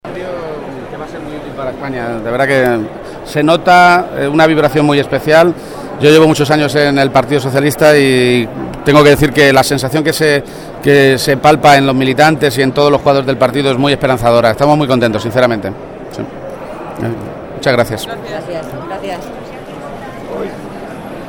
En declaraciones a los medios de comunicación, esta mañana, en Madrid, poco antes del acto de clausura del Congreso, Page ha indicado que percibe una «sensación muy especial» en el partido.